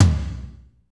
Index of /90_sSampleCDs/Northstar - Drumscapes Roland/DRM_Slow Shuffle/KIT_S_S Kit 1 x
TOM S S L0UL.wav